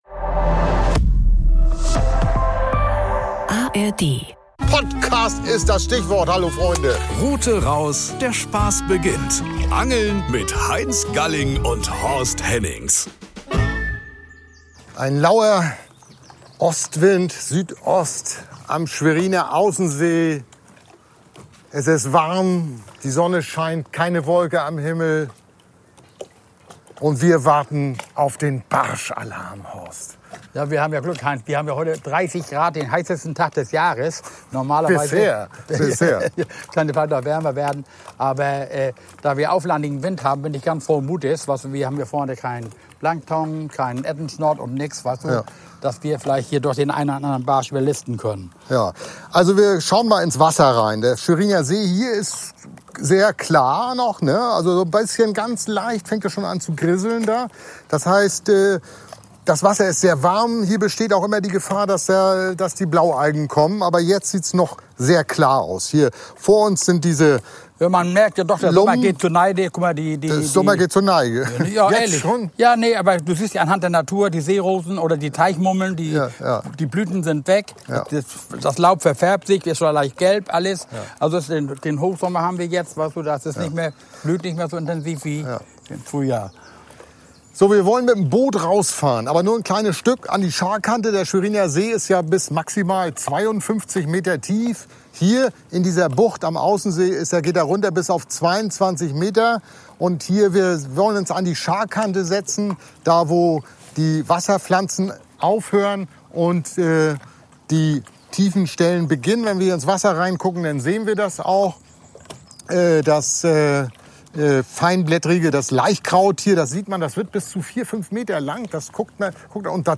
Diesmal sind sie mit einer "Anka" auf dem Schweriner See unterwegs und versuchen, Sommerbarsche zu fangen. Dabei erklären sie, welche Köder für die Angelei auf Barsch erfolgreich sind.